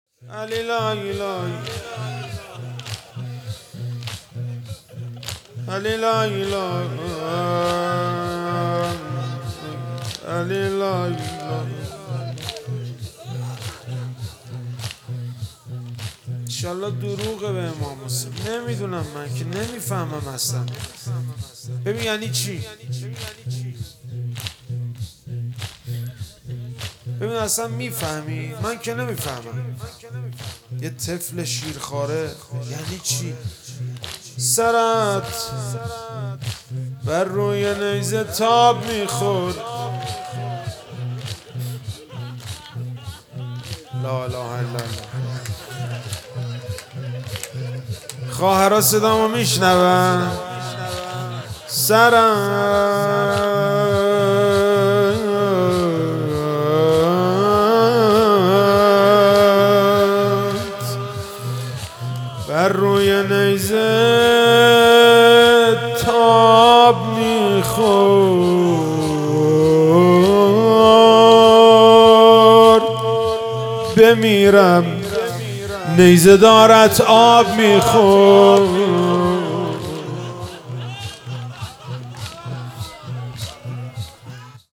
عزاداری ایام پایانی صفر - شب ششم - زمینه - سرت به روی نیزه تاب میخورد